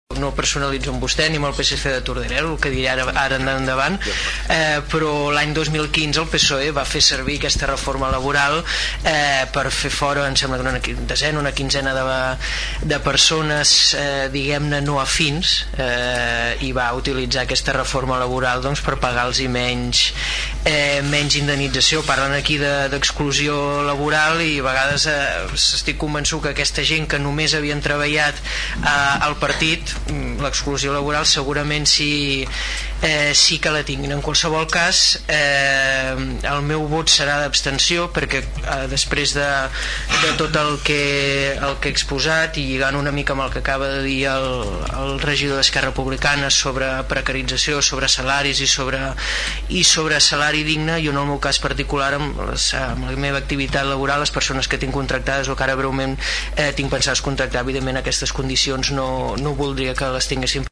El ple de l’Ajuntament de Tordera aprova una moció al voltant de les empreses multiserveis
El regidor del PP, Xavier Martín, es va abstenir perquè, tot i estar d’acord en combatre el frau i contractar més inspectors, no comparteix que es traslladin totes les culpes a la reforma laboral dels populars, recordant que s’ha reconegut a nivell internacional perquè ha donat fruits.